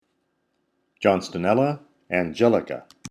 Pronunciation/Pronunciación:
John-sto-nél-la an-gé-li-ca